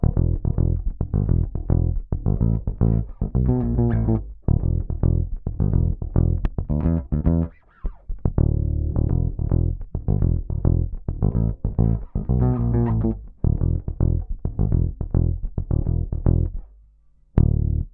DF_107_E_FUNK_BASS_02.wav